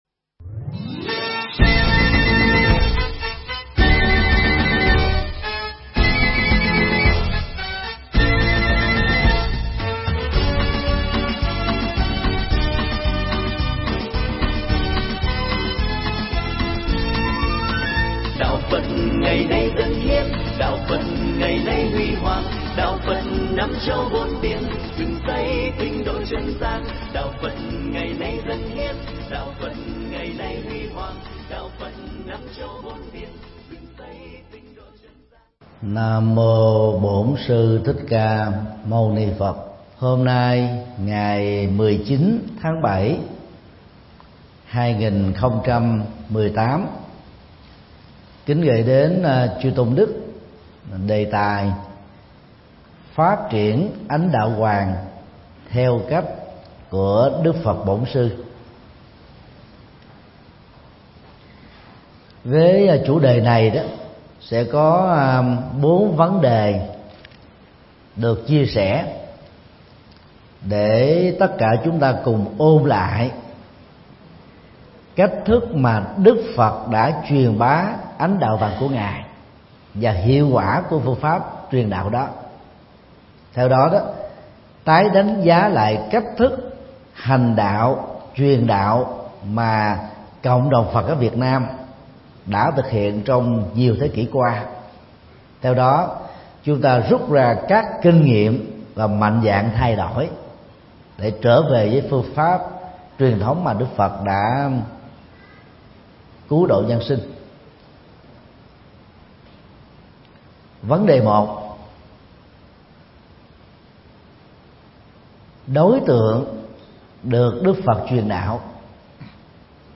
Mp3 Pháp Thoại Phát Triển Ánh Đạo Vàng Theo Cách Của Đức Phật Bổn Sư – Thượng Tọa Thích Nhật Từ giảng tại Thiền viện Minh Đăng Quang (Vĩnh Long), ngày 19 tháng 7 năm 2018